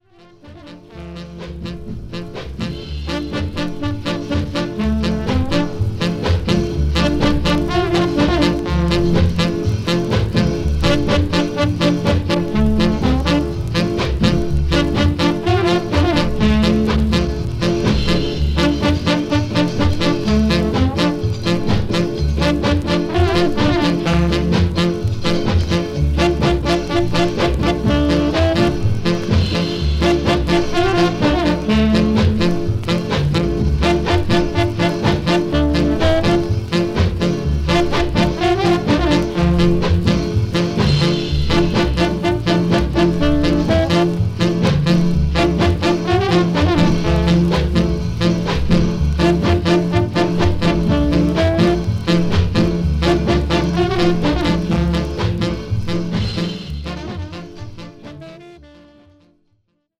再生は良好です。